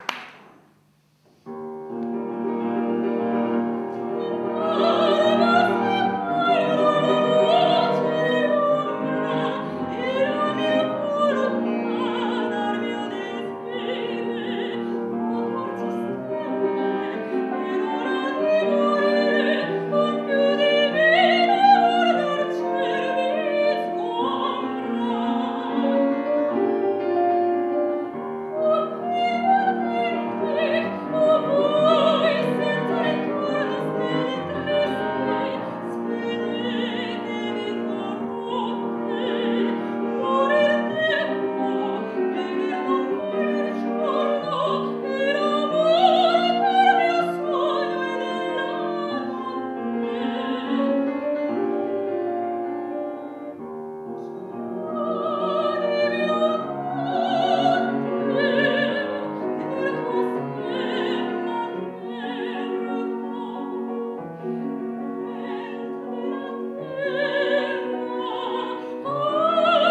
III OTTOBRE MUSICALE A PALAZZO VALPERGA
soprano
pianoforte